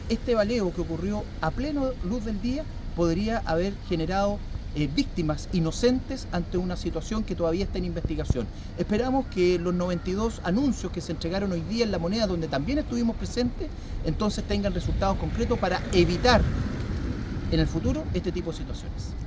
balacera-concepcion-alcalde.mp3